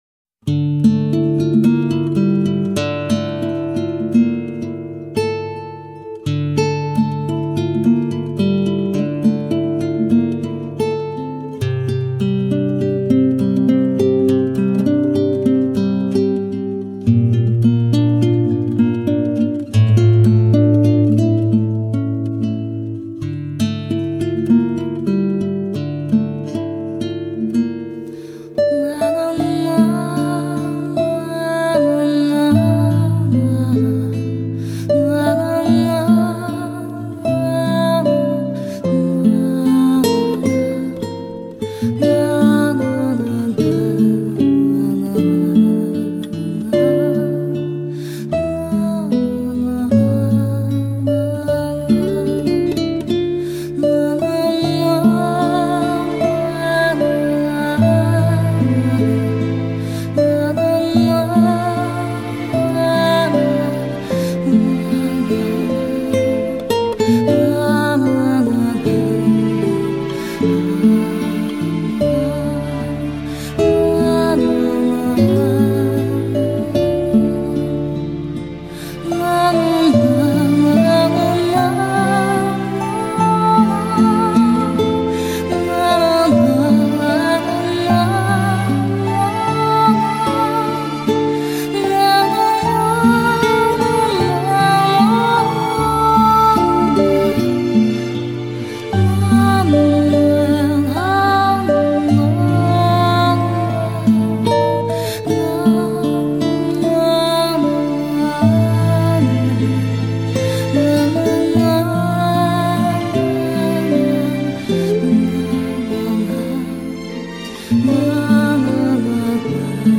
反复的吟唱，反复的回味，让那份痛与悲更加的清晰与明显了。
不是沉重，却好似沉醉！